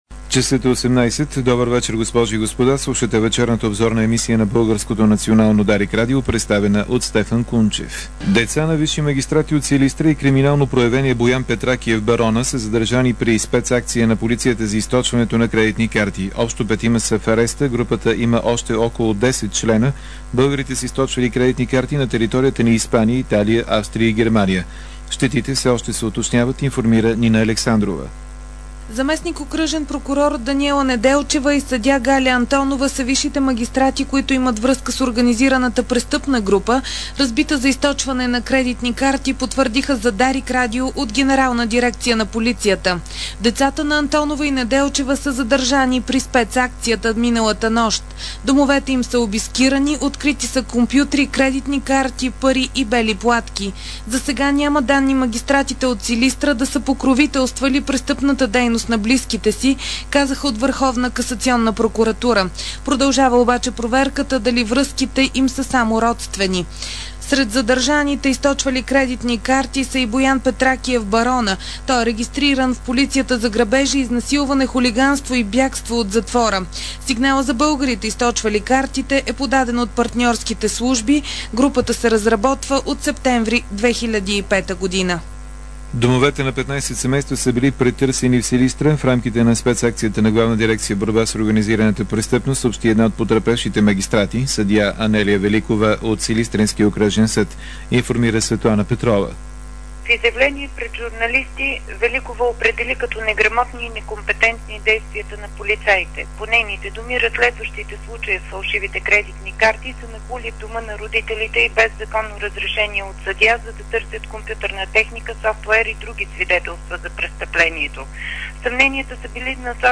DarikNews audio: Обзорна информационна емисия 01.06.2006